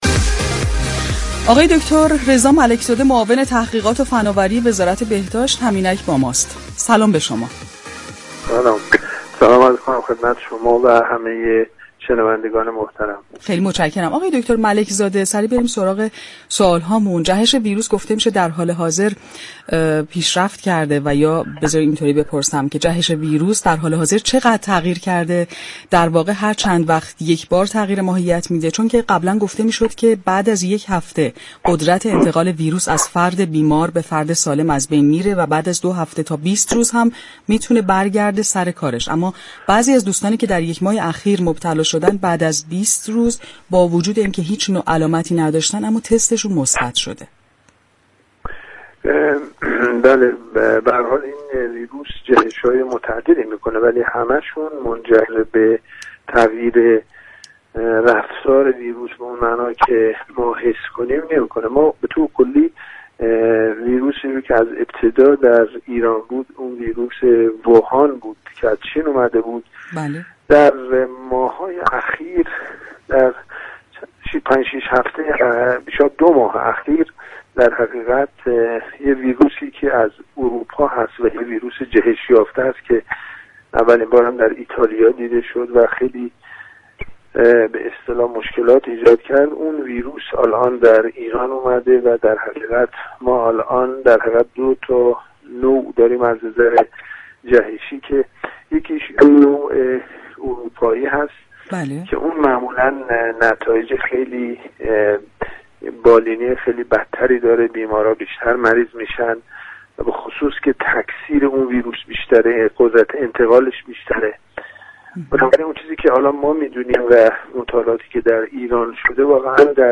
رضا ملك زاده در گفتگو با برنامه تهران كلینیك رادیو تهران درباره جهش ویروس كرونا گفت: این ویروس جهش های متعددی دارد؛ اما تغییرات آن به نحوی صورت می‌گیرد كه ما متوجه آن نمی شویم.